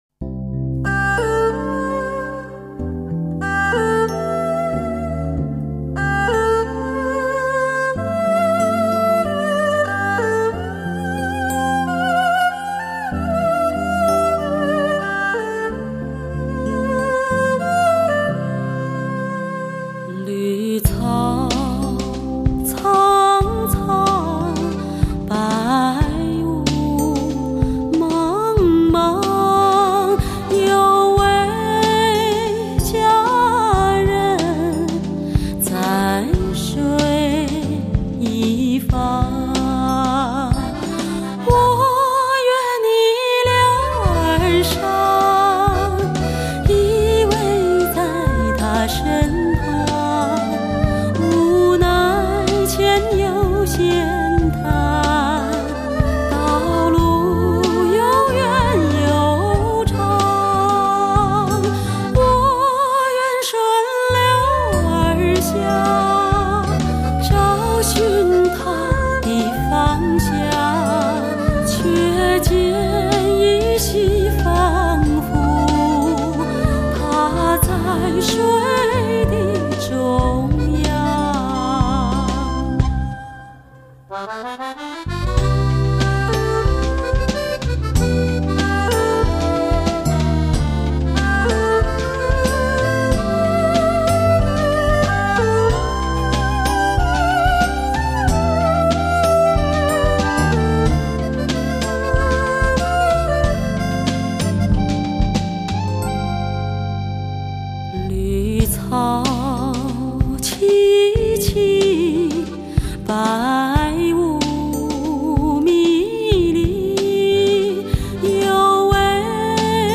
美不可言的磁性音色
类型: 天籁人声